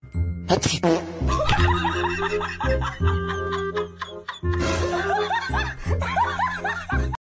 Suara Bersin dan Kentut
Nada notifikasi kentut WhatsApp Notifikasi WA Bersin
suara-bersin-dan-kentut-id-www_tiengdong_com.mp3